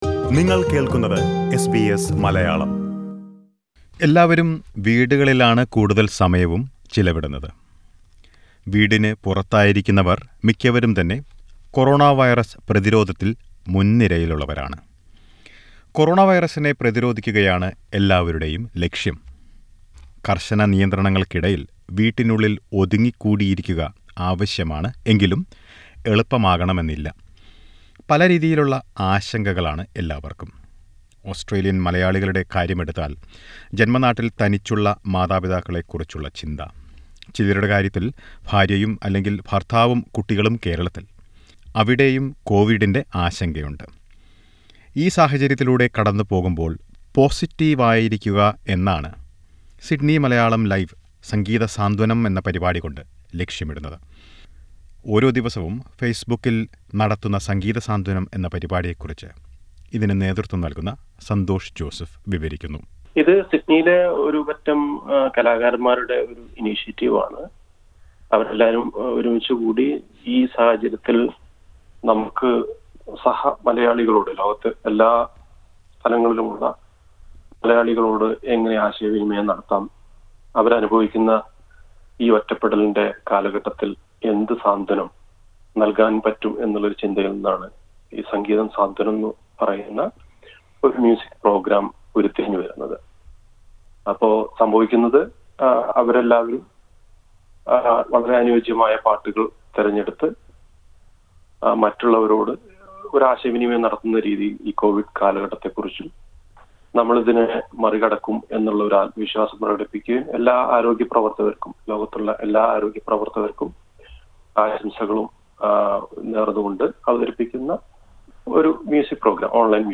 Many get connected through online music events. Listen to a report.